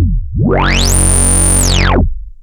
OSCAR  9 F#1.wav